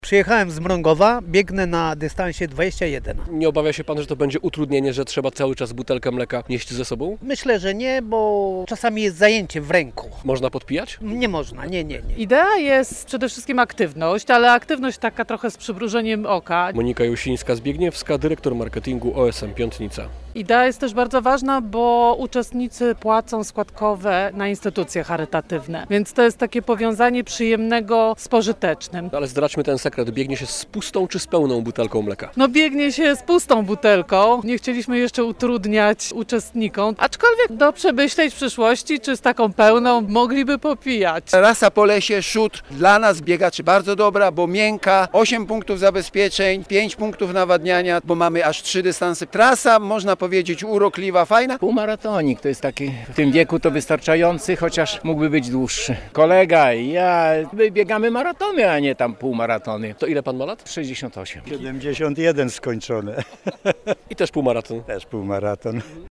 250 biegaczy z całej Polski bierze udział w VI Biegu z Butelką Mleka w Giełczynie koło Łomży - relacja